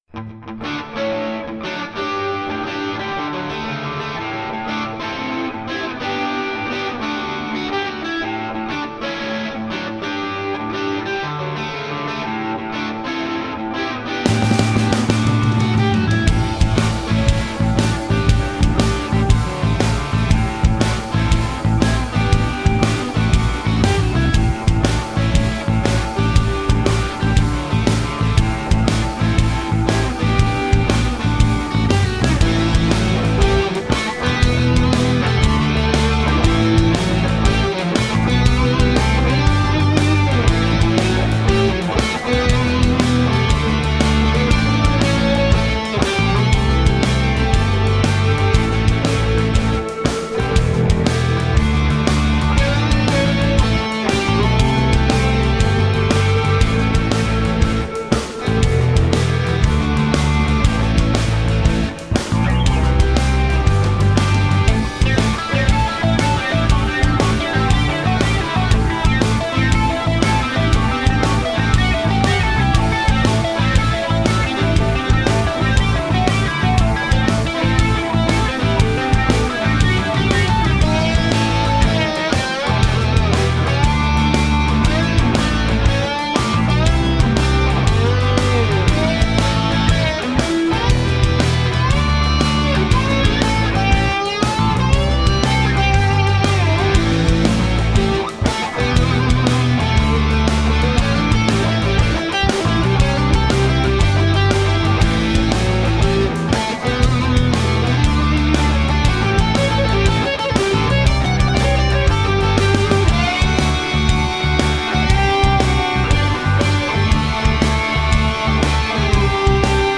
Quality guitar driven instrumental music.